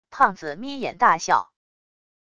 胖子眯眼大笑wav音频